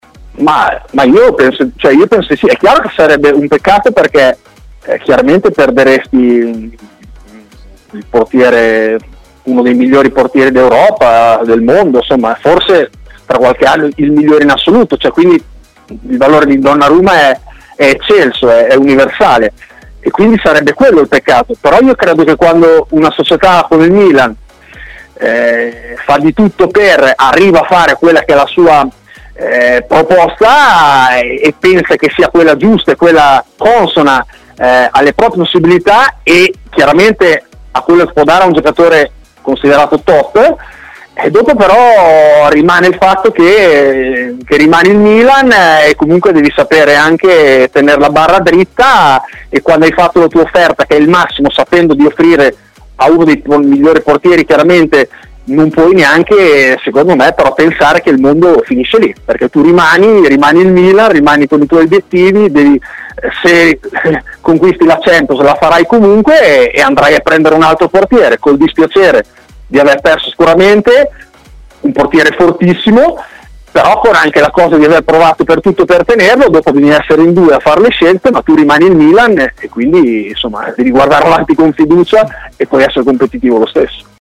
Fabio Bazzani, intervistato da TMW Radio, si è espresso sull'eventuale addio di Donnarumma a parametro zero: "Chiaramente perderesti uno dei migliori al mondo, tra qualche anno forse il migliore in assoluto, ma il Milan è arrivato comunque a fare una proposta che pensa giusta e consona rispetto alle proprie possibilità.